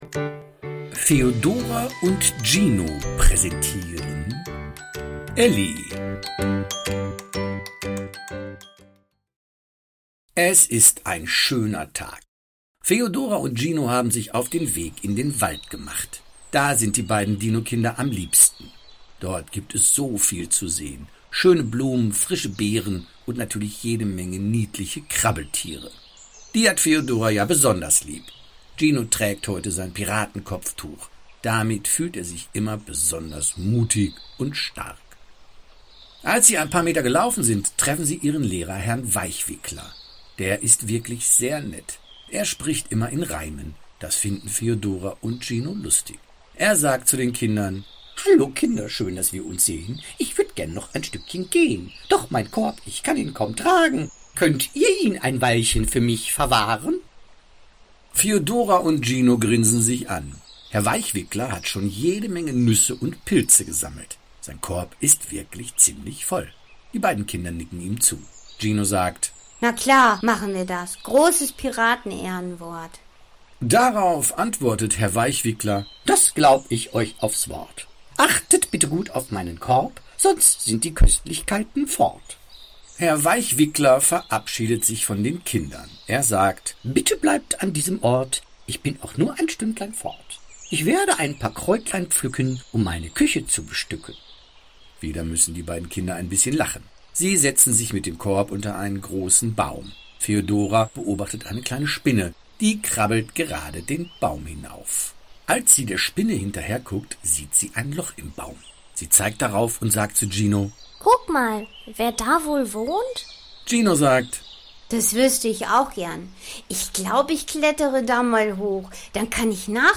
Elli – The audio play based on the popular picture book!
A lovingly narrated version full of emotion, ideal for falling asleep, relaxing, or simply listening.